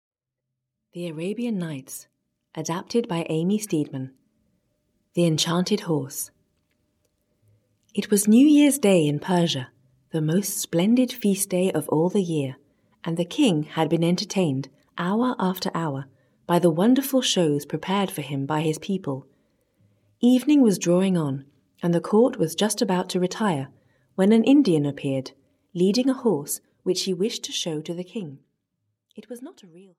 The Enchanted Horse, a 1001 Nights Fairy Tale (EN) audiokniha
Ukázka z knihy